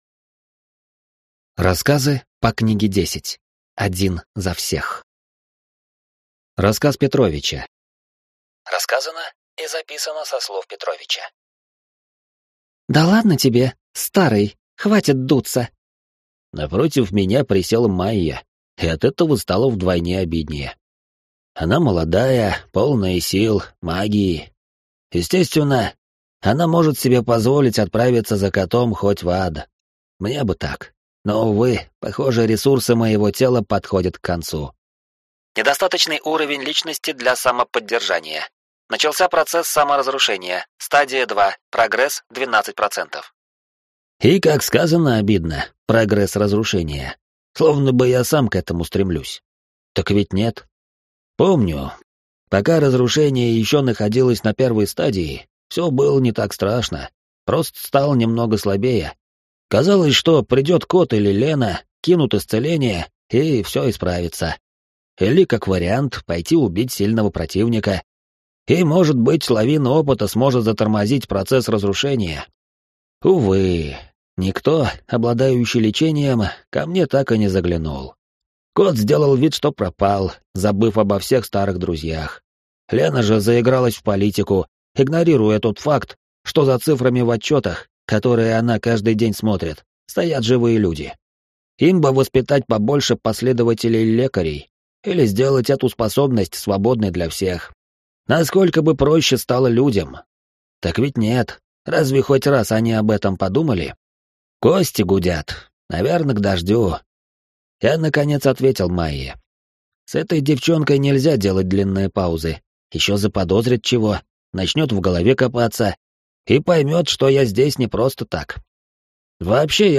Аудиокнига Мир Карика. Сборник рассказов | Библиотека аудиокниг
Прослушать и бесплатно скачать фрагмент аудиокниги